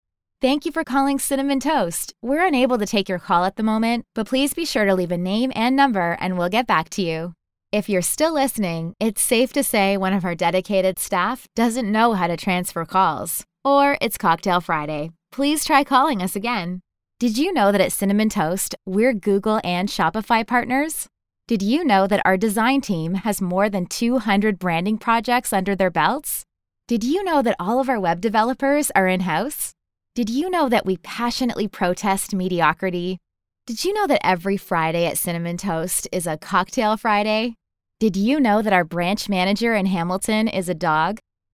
Female
I’m a Canadian voiceover artist with a native North American accent and a warm, youthful, relatable sound.
Microphone: Rode NT1-A